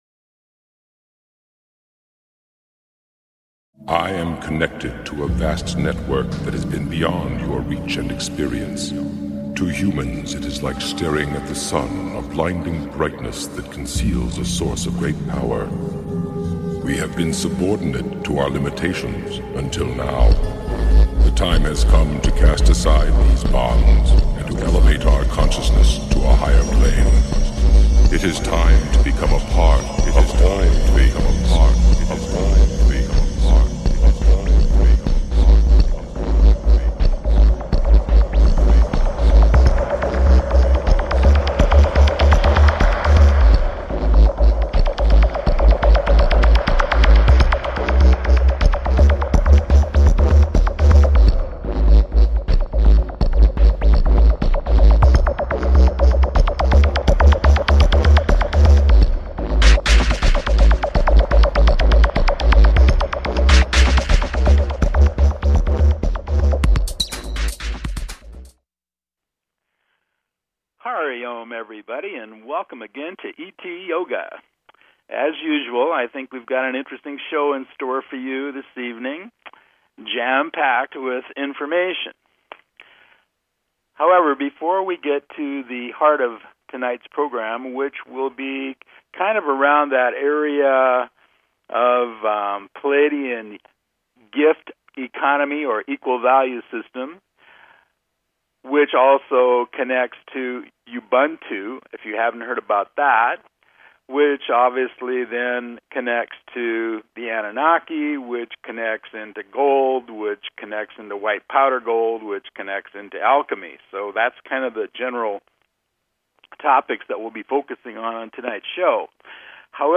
Show Tags ET Yoga Archive Category Education Physics & Metaphysics Spiritual Technology ET Yoga Please consider subscribing to this talk show.